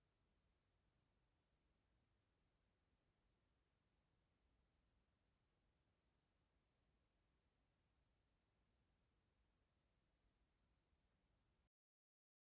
Der große Sprecherraum-Mikrofonvergleich
Das war jetzt alles noch nicht entrauscht, es geht mir ja eher um den Klang und den Raum-Eindruck.